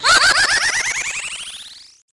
描述：带延迟的长笛环（120 bpm）
Tag: 回波 循环 电子 长笛 延迟